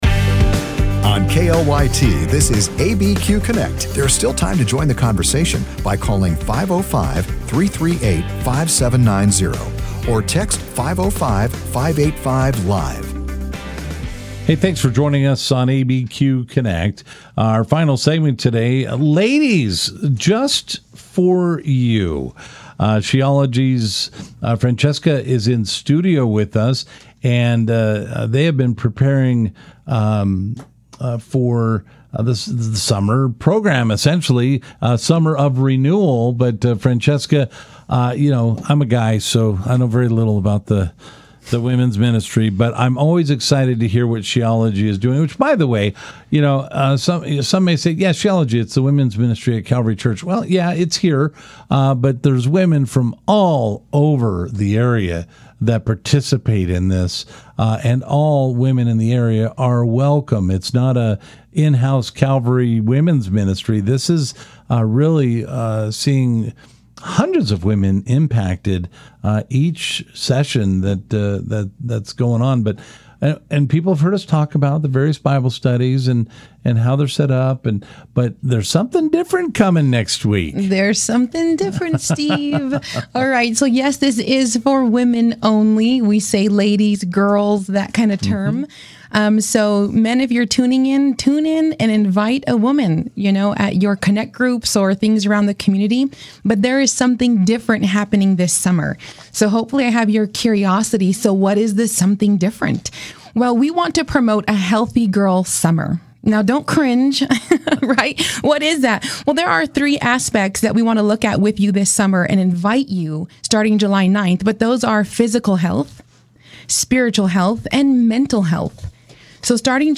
Live, local and focused on issues that affect those in the New Mexico area. Tune in for conversations with news makers, authors, and experts on a variety of topics.